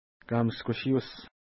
Kamashkushiut Next name Previous name Image Not Available ID: 191 Longitude: -60.7307 Latitude: 55.4702 Pronunciation: ka:miskuʃi:us Translation: Grassy Place Feature: lake Explanation: There is grass all around the lake.